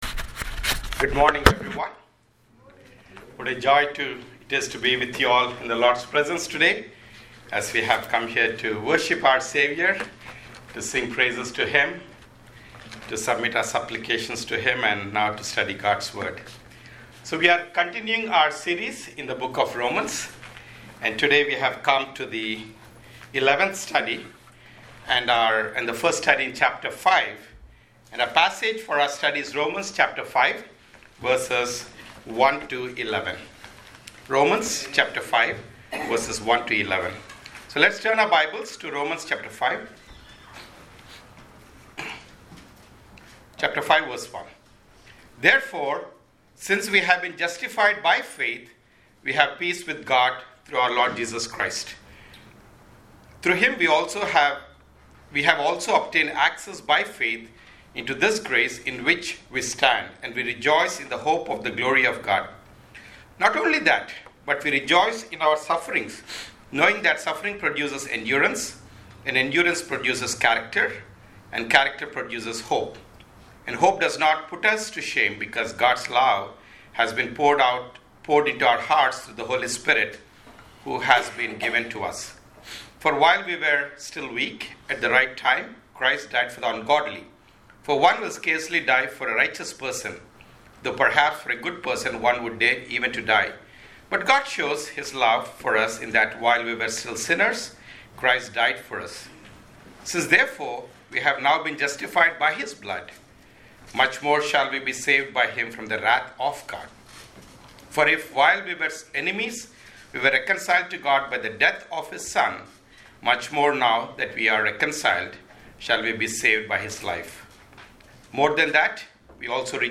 Sermons – Ardsley Bible Chapel